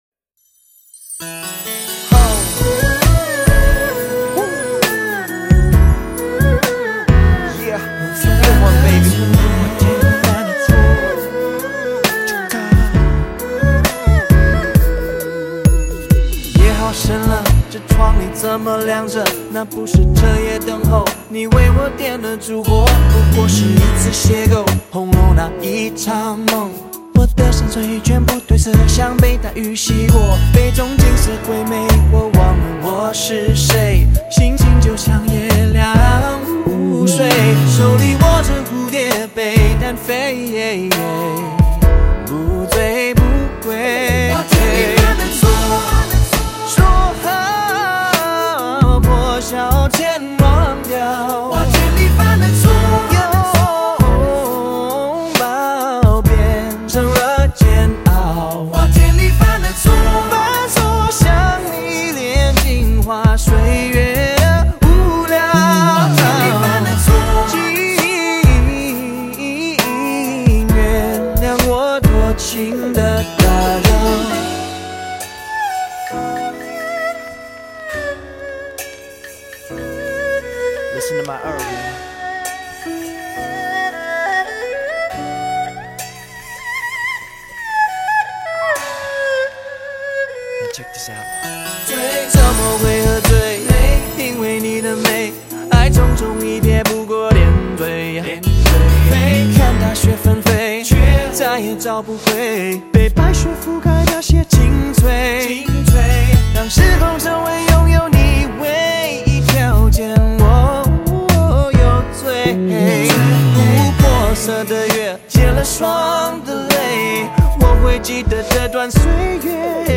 来自西洋乐风R&B的自由转音其Free的唱法正与京剧里特别将唱词中单个字拉长转音的戏剧性相似